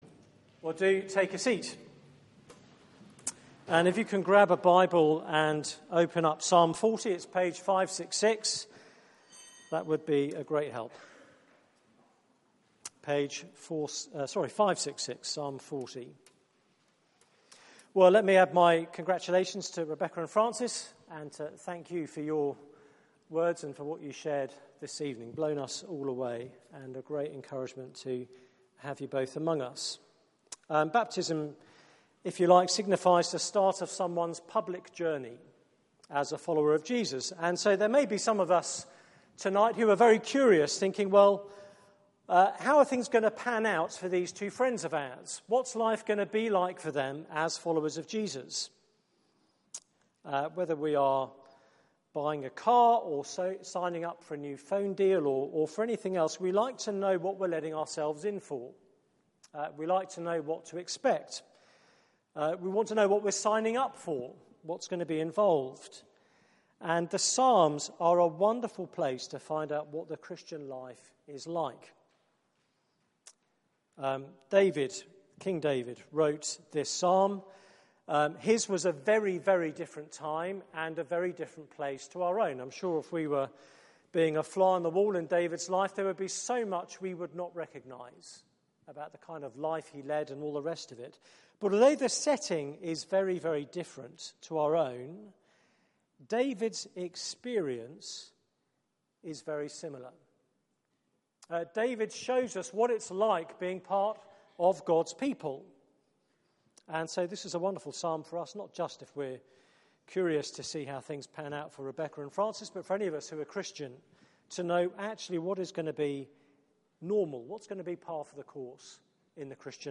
Passage: Psalm 40 Service Type: Weekly Service at 4pm